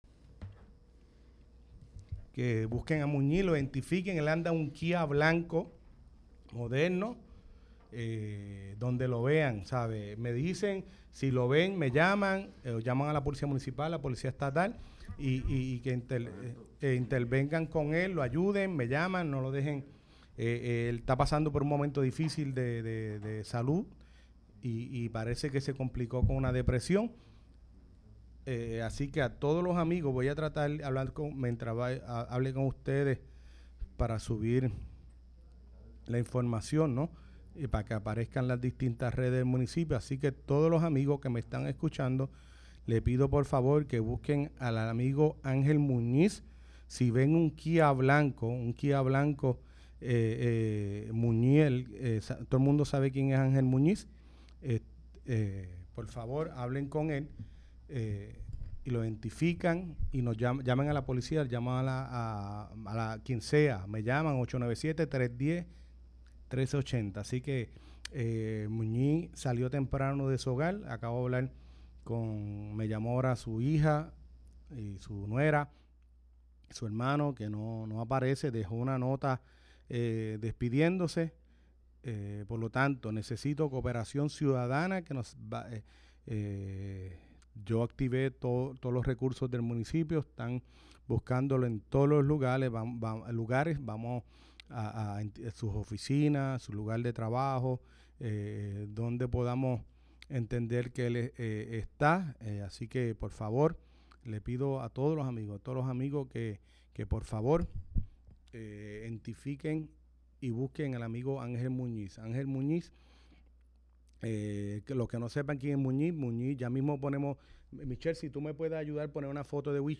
El honorable alcalde de Lares, Fabián Arroyo, junto a su equipo de trabajo nos informan sobre todo las novedades del municipio.